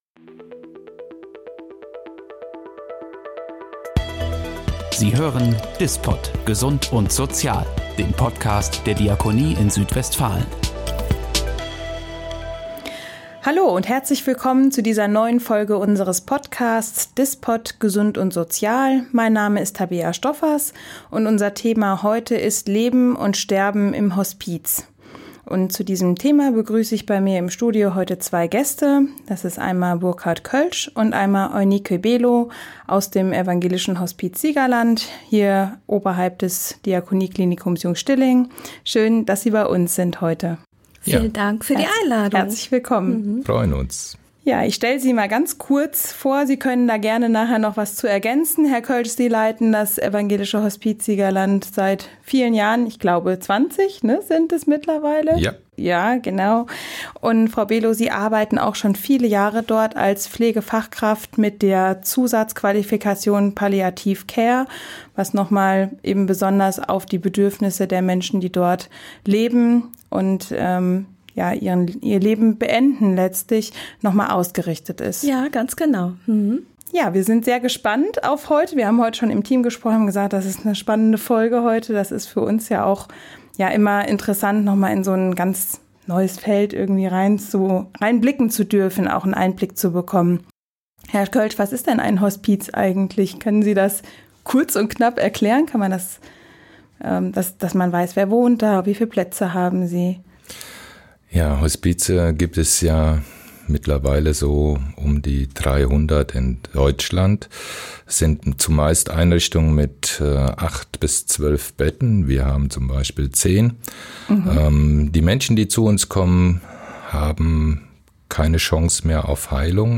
Aber wie fühlt es sich an, an einem solchen Ort zu arbeiten, wo der Tod so präsent ist? Im Studio